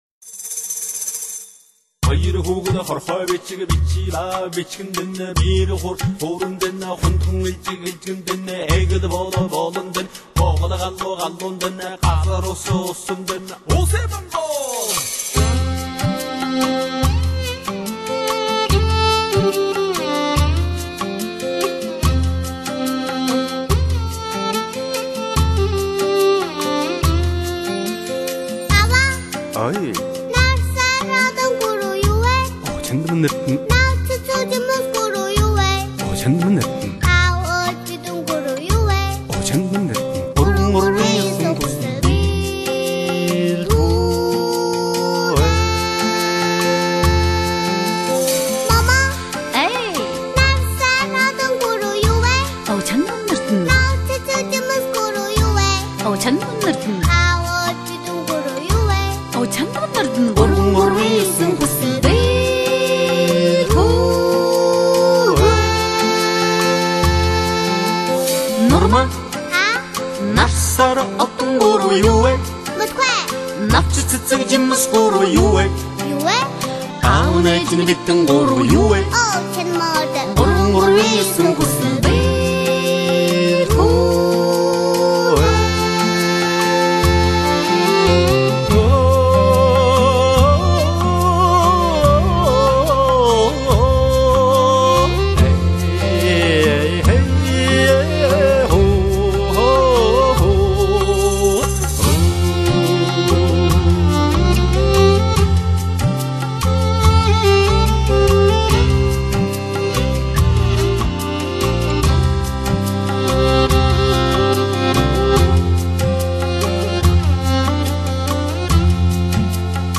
用地道的蒙语演唱
她的声音稚气十足，音乐的律动出奇地悦人耳目